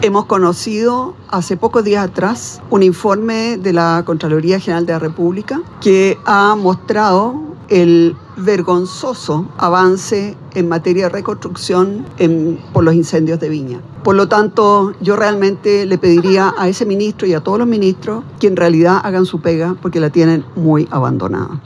Durante un acto de campaña en Providencia, este sábado la candidata presidencial de Chile Vamos, Evelyn Matthei, criticó el apoyo del ministro de Vivienda y Urbanismo, Carlos Montes, hacia la candidata del Socialismo Democrático, Carolina Tohá.